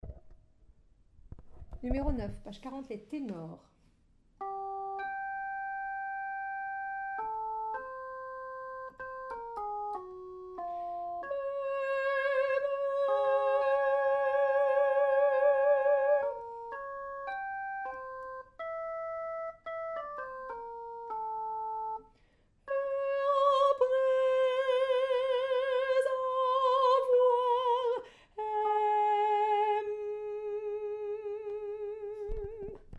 Soprano 2